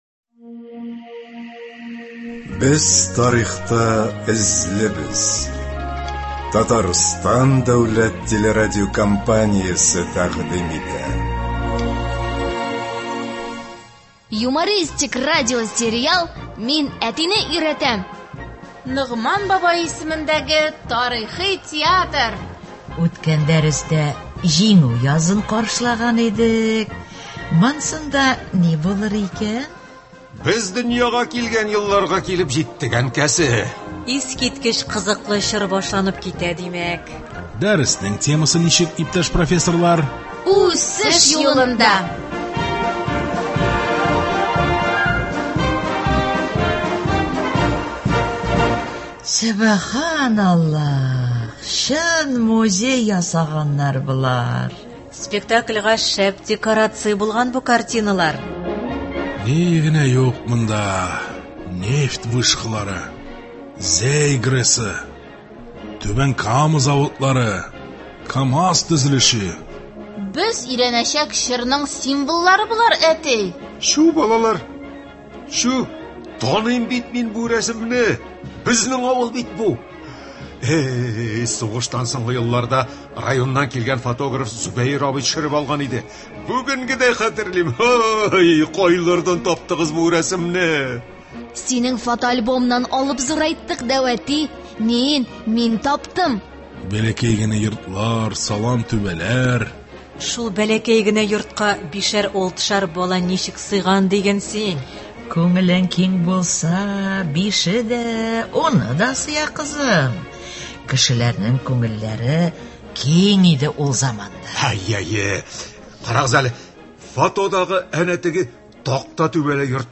Радиосериал.